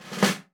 T5LV  ROLL.wav